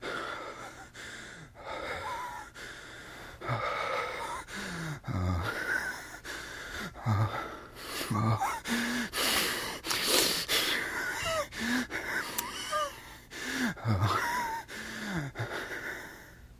HeavyMaleBreathing AZ175701
Heavy Male Breathing, W Wheezes And Moans. 1